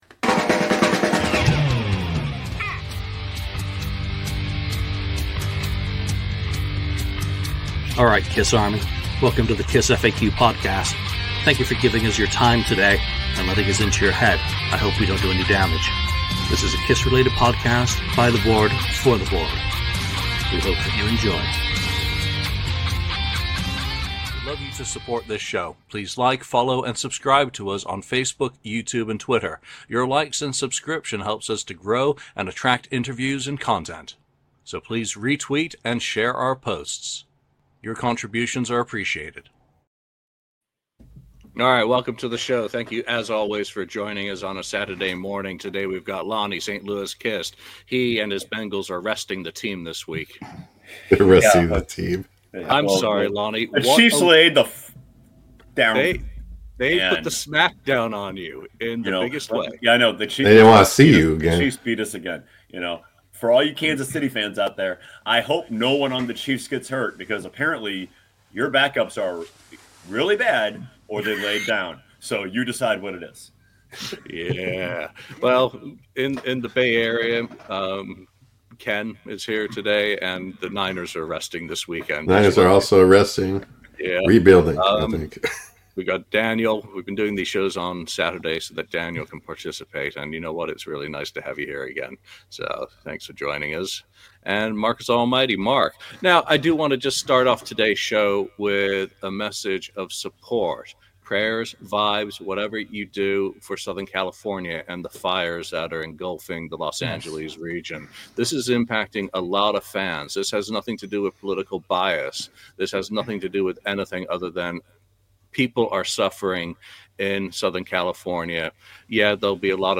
The KissFAQ Podcast is a weekly chat show featuring members of the KissFAQ message board and other guests discussing a wide variety of KISS-related topics.